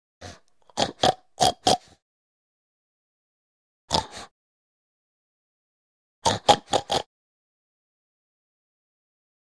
Pig Snorts - 3 Effects; Pig Snorts.